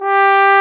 Index of /90_sSampleCDs/Roland LCDP12 Solo Brass/BRS_Trombone/BRS_Tenor Bone 4
BRS BONE  0B.wav